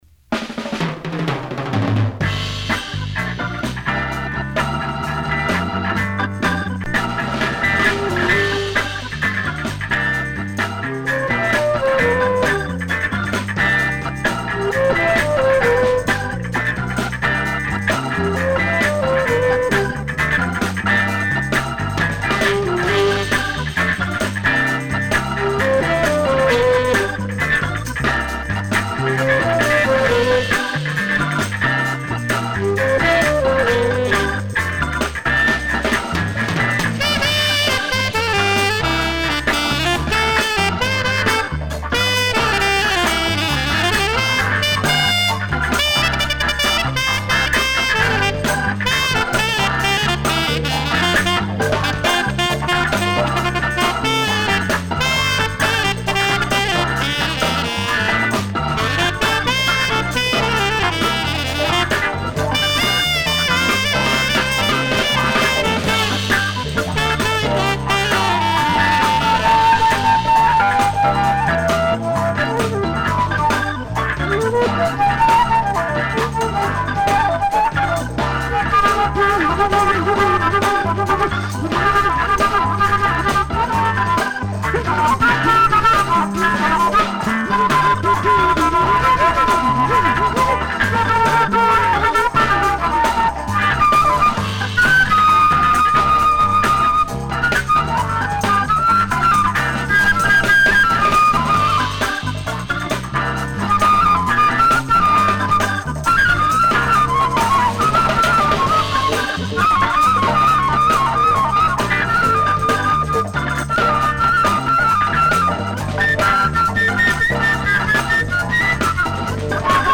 soul jazz cover